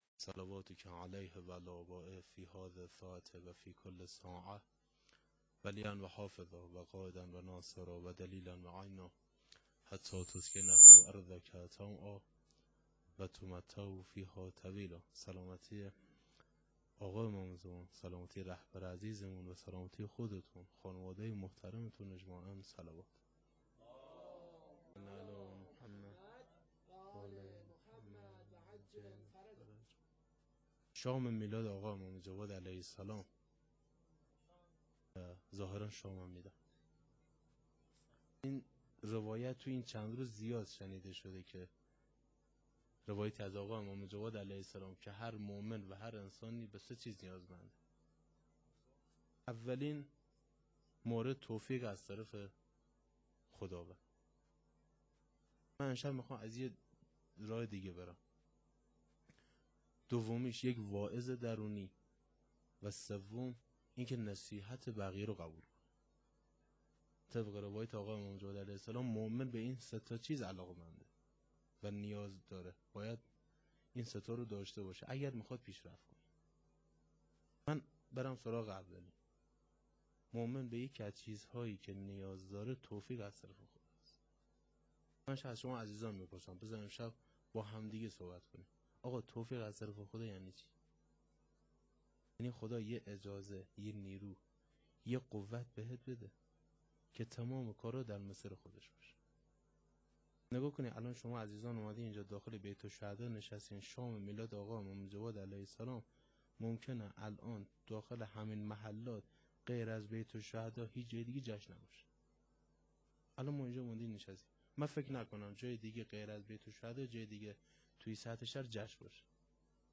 سخنرانی.wma